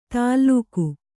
♪ tāllūku